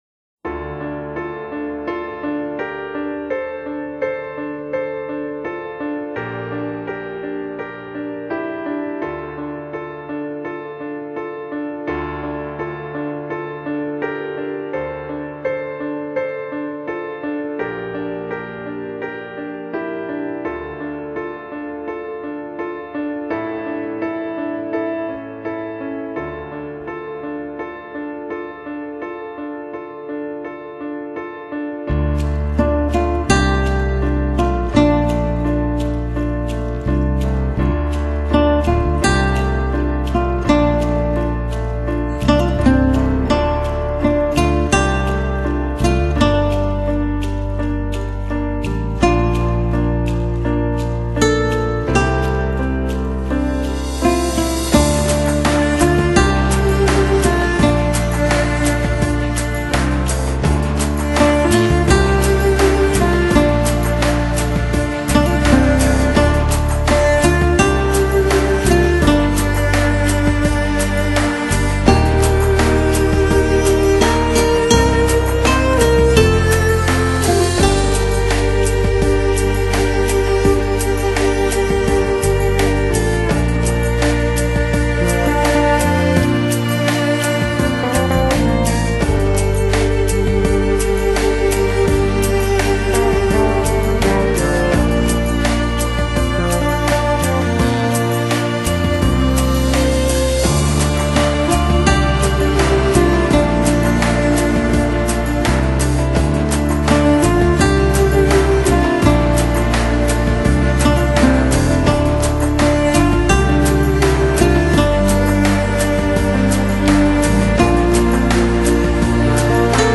这是非常优秀和细腻的音乐，并且蕴含着丰富的变化和深度。